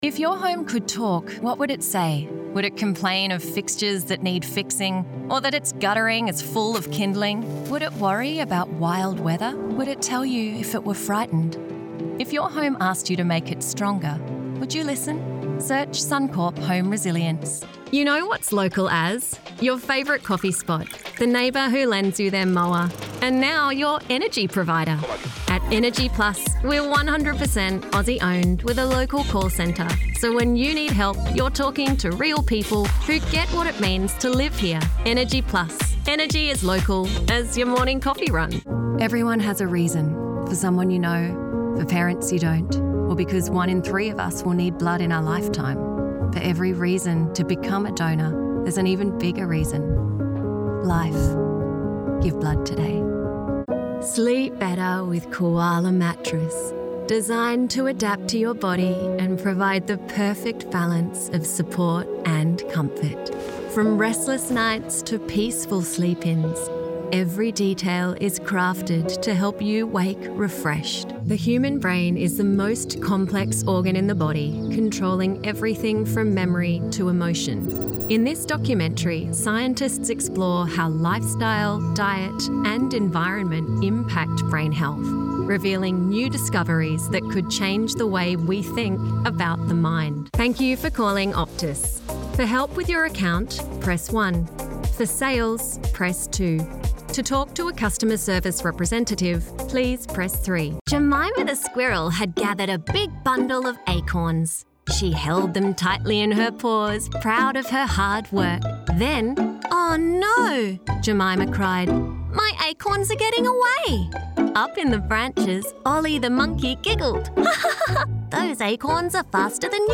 Never any Artificial Voices used, unlike other sites.
Foreign & British Female Voice Over Artists & Actors
Adult (30-50)